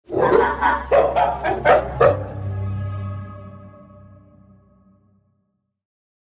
ホラー・スリラー系_2（夜の森に潜む野犬・ゾンビ犬をイメージした効果音・場面転換）